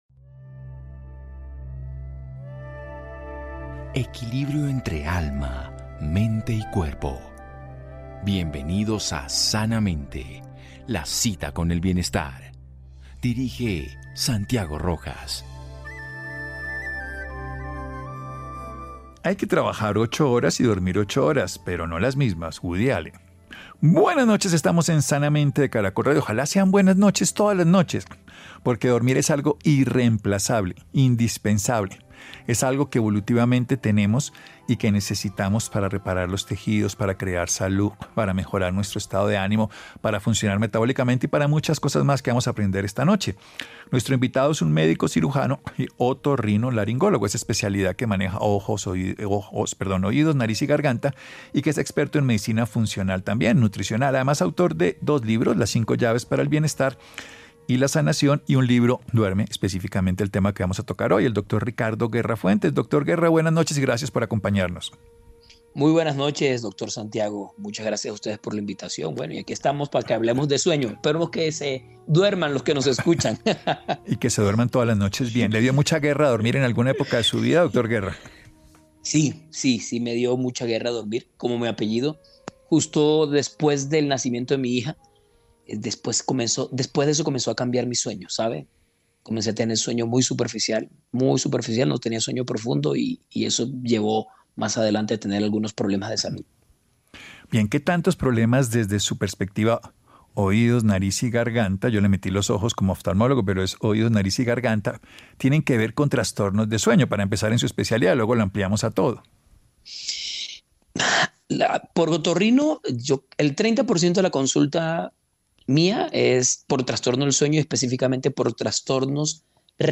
Parar con los ronquidos a la hora de dormir es posible sí se tienen buenos hábitos de vida. Un experto conversa sobre las causas de los ronquidos.